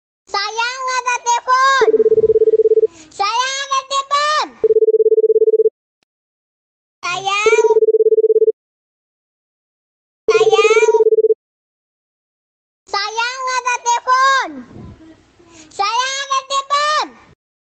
Nada dering Sayang ada telepon - Versi Bayi
Kategori: Nada dering
Keterangan: Download nada dering Sayang ada telpon versi animasi, Bayi, lucu yang lagi viral di TikTok untuk WA dan semua hp gratis di sini.
nada-dering-sayang-ada-telepon-versi-bayi-id-www_tiengdong_com.mp3